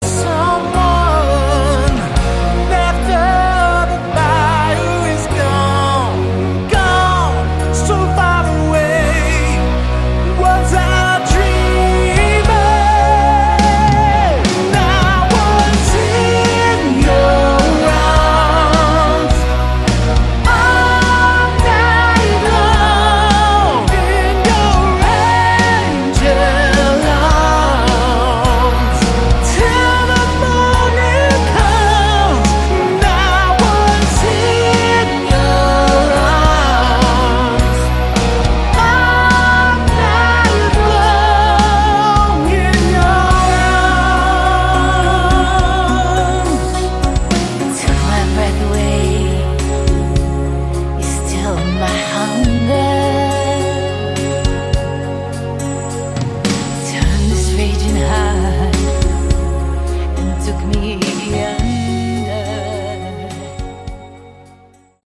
Category: AOR/ Melodic Rock